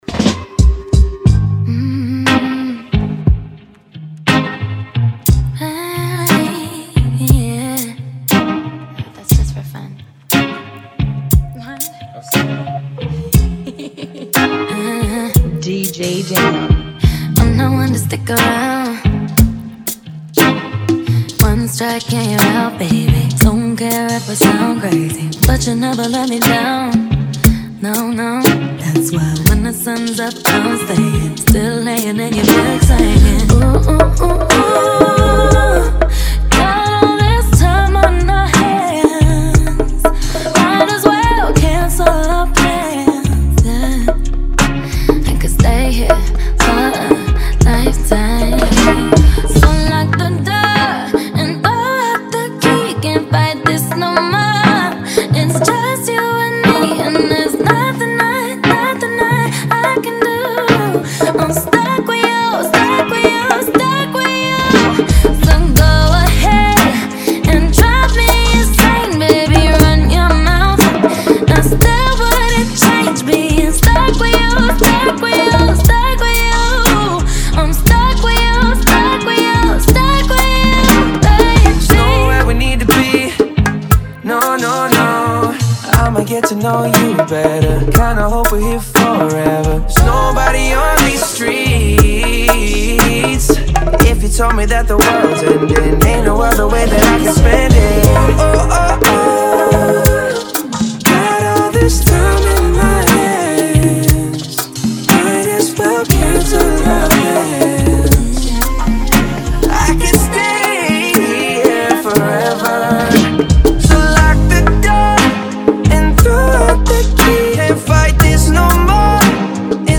118 BPM
Genre: Bachata Remix